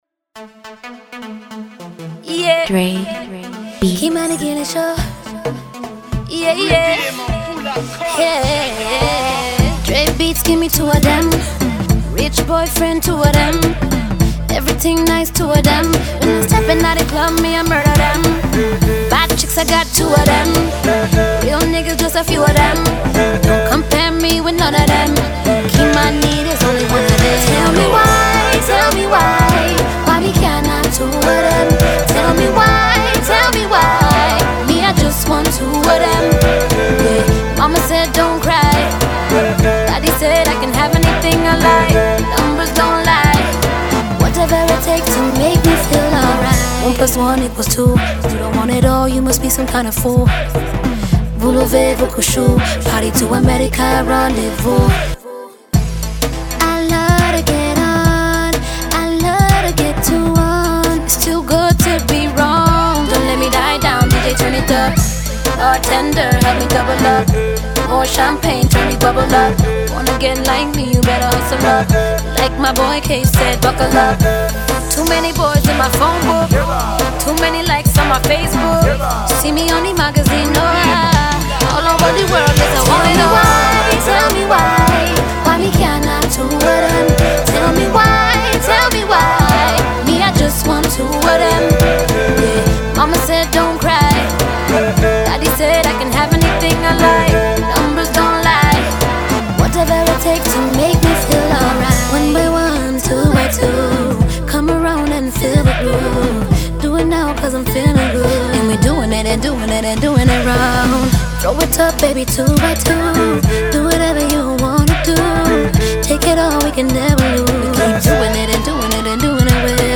playful new track